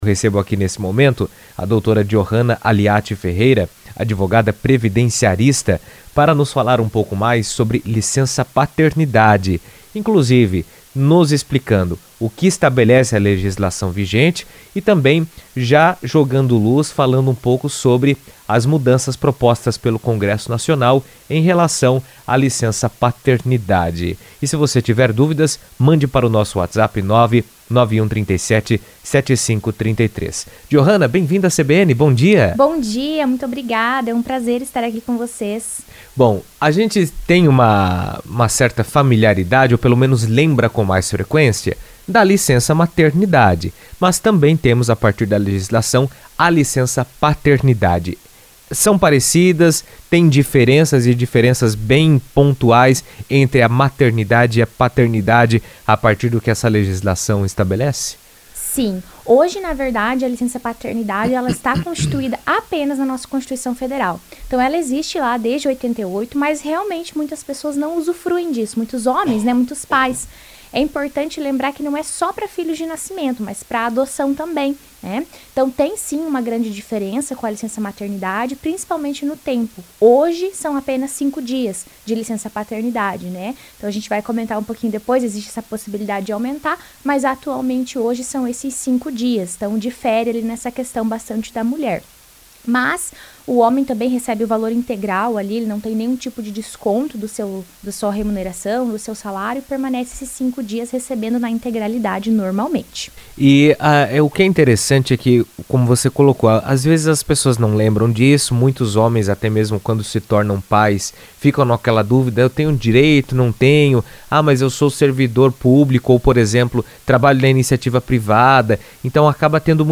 O Congresso aprovou recentemente novas medidas relacionadas à licença-paternidade, ampliando o tempo que os pais podem dedicar aos cuidados do bebê nos primeiros meses de vida. A iniciativa reforça a importância da presença do pai no desenvolvimento infantil e na rotina familiar. Em entrevista à CBN